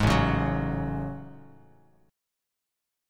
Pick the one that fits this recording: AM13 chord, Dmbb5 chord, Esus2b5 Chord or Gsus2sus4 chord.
AM13 chord